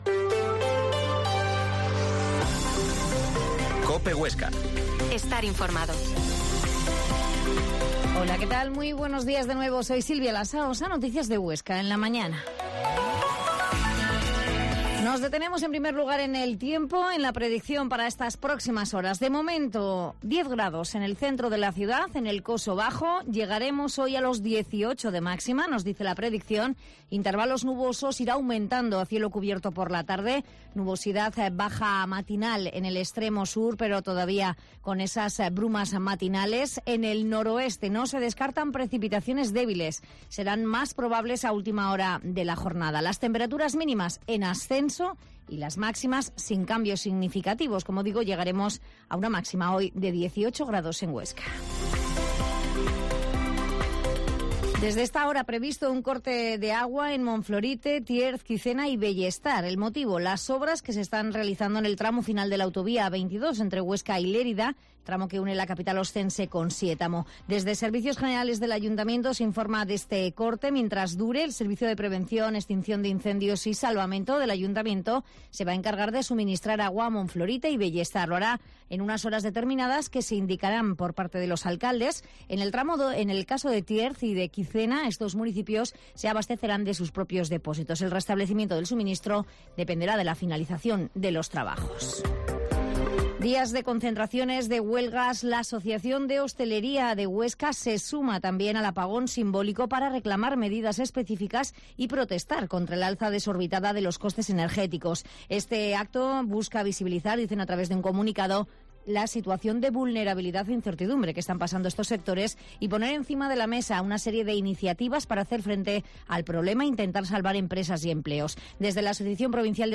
La Mañana en COPE Huesca - Informativo local